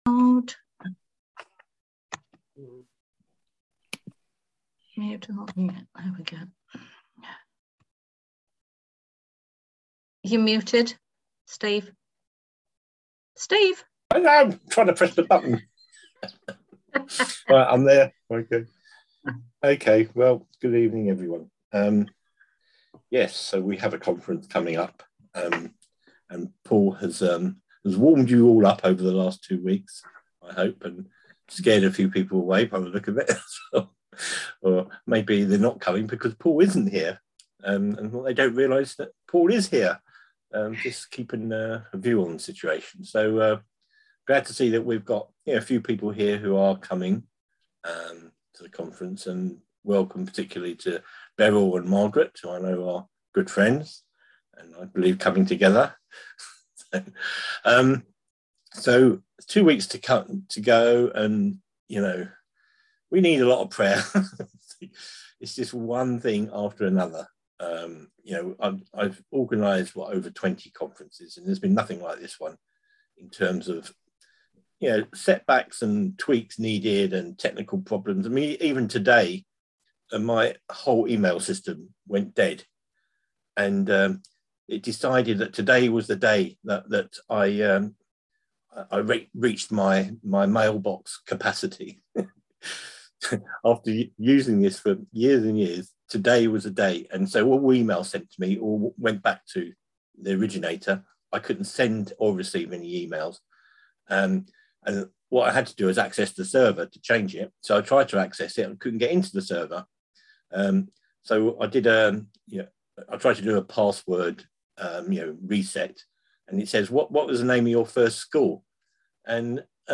On February 17th at 7pm – 8:30pm on ZOOM